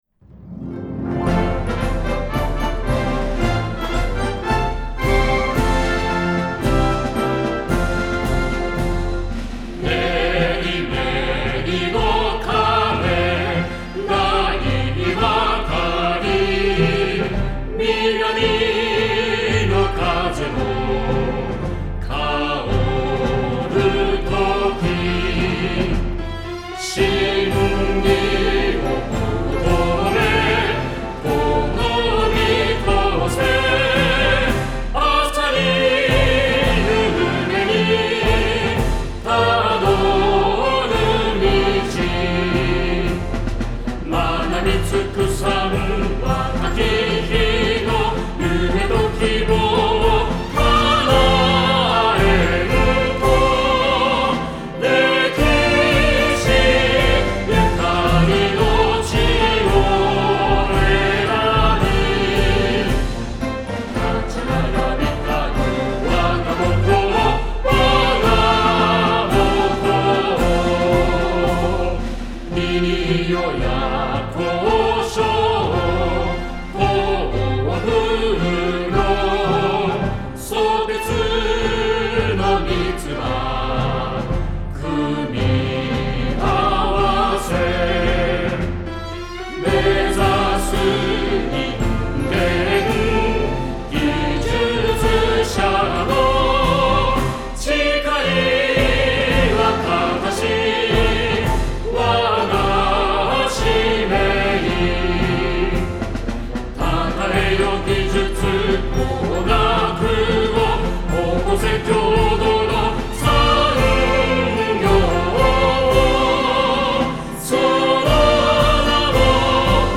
校歌（音声MP3）はこちら